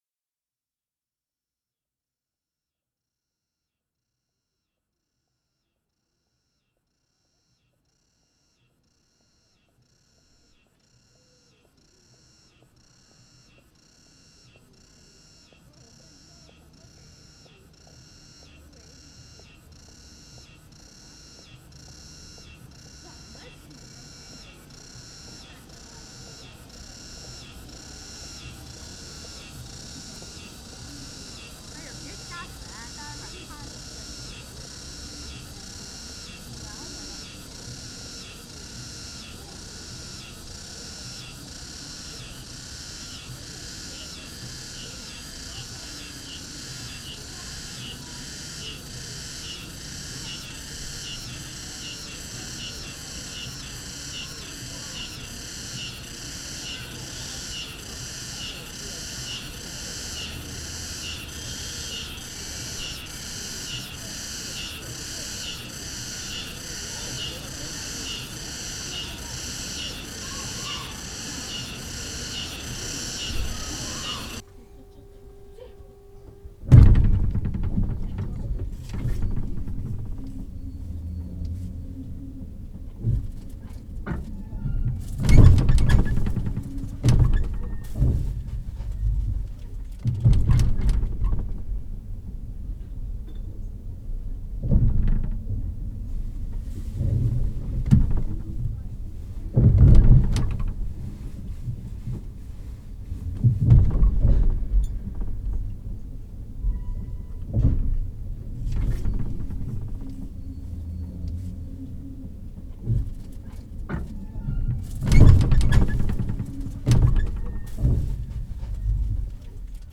TransMongolian is a composition of unprocessed field recordings composed in soundscapes.
Portrait 2 (Recorded in China, 08/2010)
Onto “Portrait two” and we’ve now moved in China, and this dead on seven minute track starts with a weird looped & growing mass of chipping textures(which sounds like mechanical birds) & building people chatter.
The track ends with fading rail scraping sounds.
02-china-excerpt.mp3